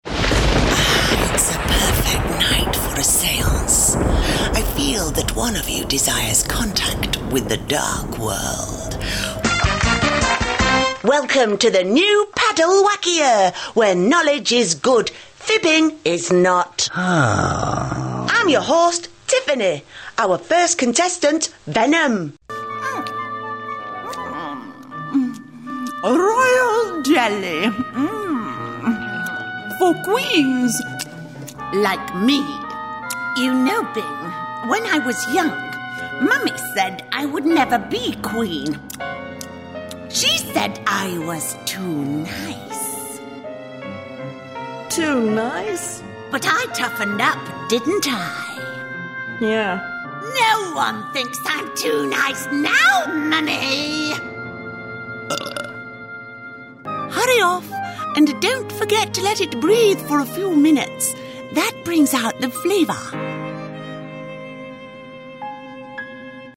Experienced Female British Voice Over, Fast Turnaround, Professional Service
Sprechprobe: Sonstiges (Muttersprache):